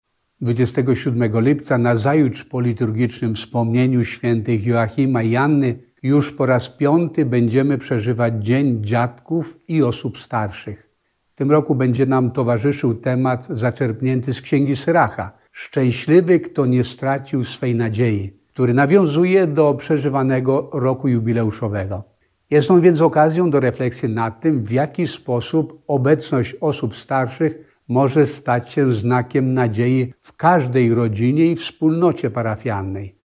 – mówił przewodniczący Konferencji Episkopatu Polski, abp Tadeusz Wojda SAC.